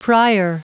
Prononciation du mot prier en anglais (fichier audio)
Prononciation du mot : prier